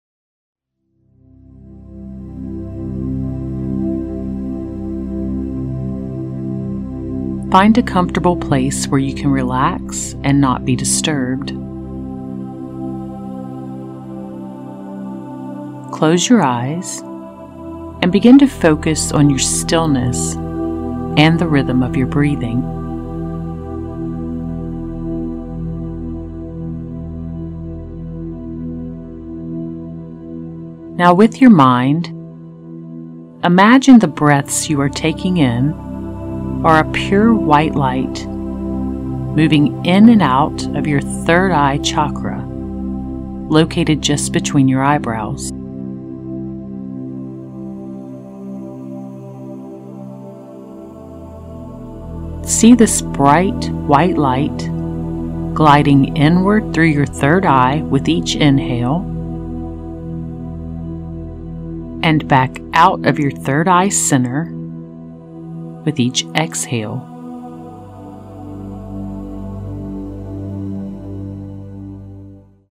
This law of attraction guided meditation will transport you into a state of Pure Consciousness so you can manifest anything you want from the field of Infinite Possibility!
It is also combined with a 528Hz Solfeggio Frequency, known as the “miracle tone” and used for positive transformation.